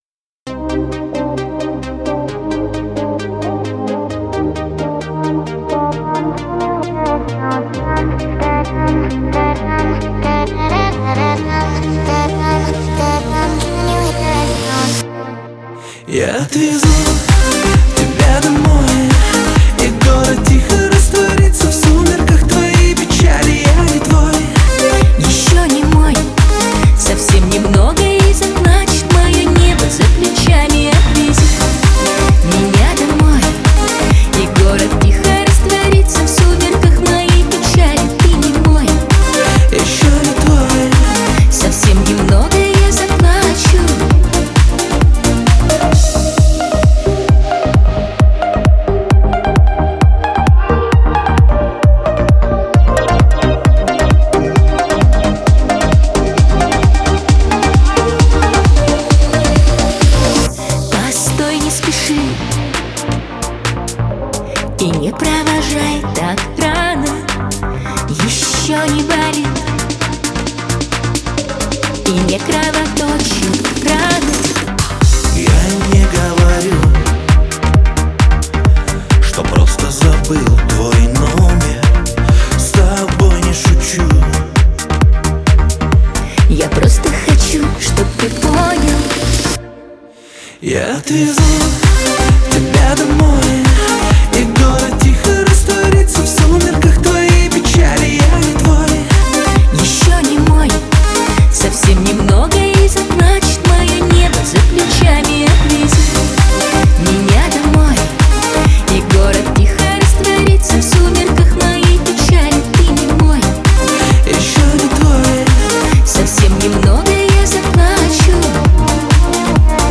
Танцевальная музыка
танцевальные песни